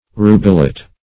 \ru"be*let\ (r[udd]"b[-e]*l[e^]t)